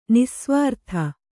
♪ nissvārtha